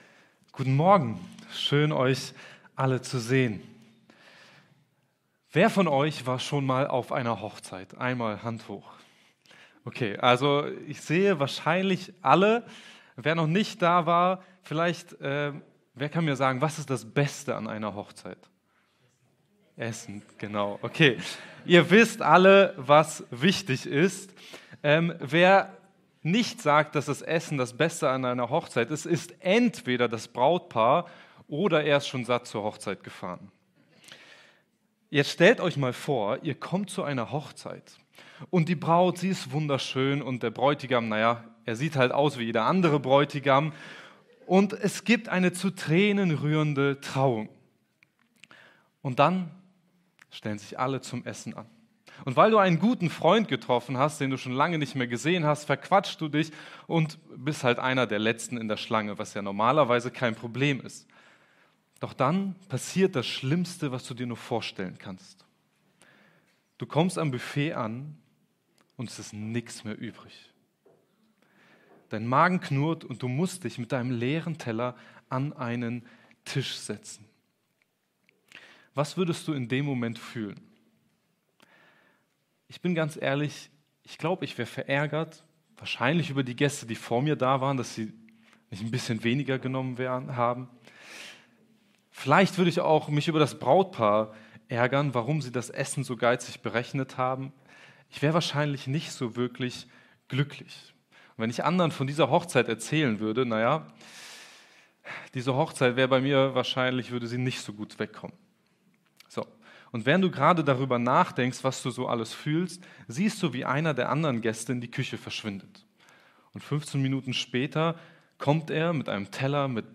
ein Gott der Freude Prediger